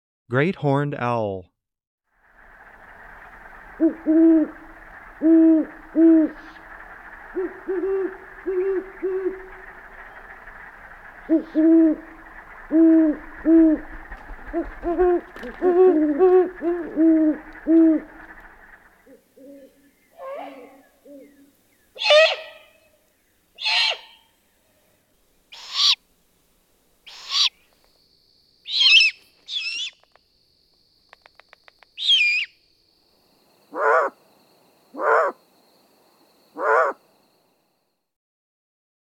great_horned_owl.m4a